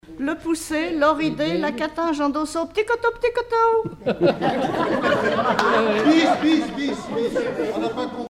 formulette enfantine : jeu des doigts
Collectif-veillée (1ère prise de son)
Pièce musicale inédite